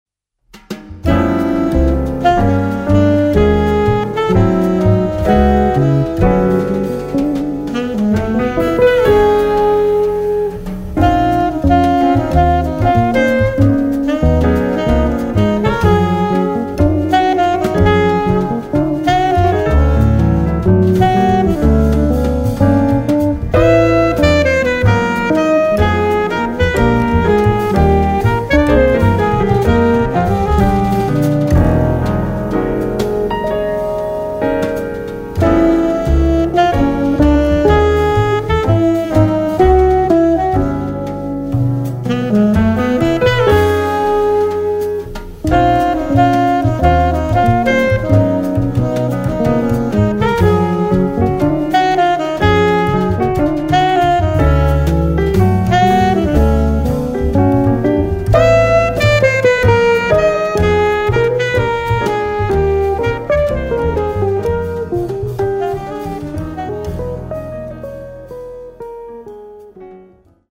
guitar
sax
piano
bass
drums